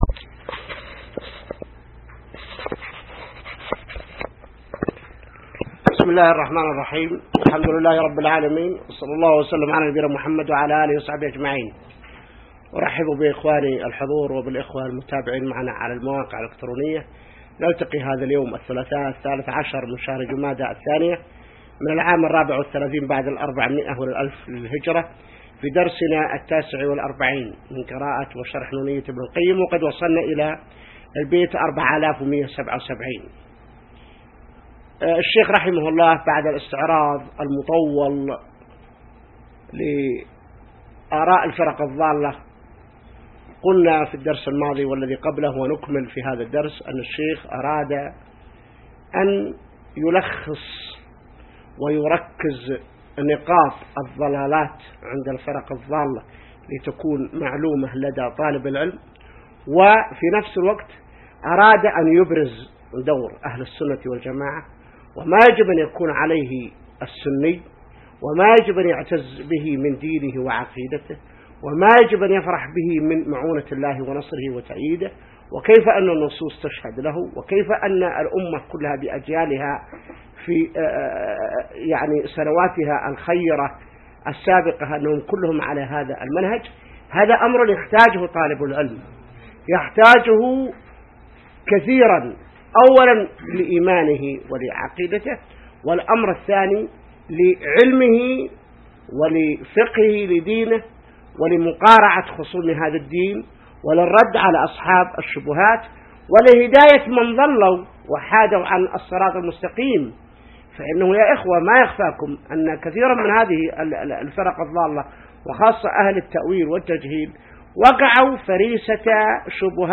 الدرس 49 من شرح نونية ابن القيم | موقع المسلم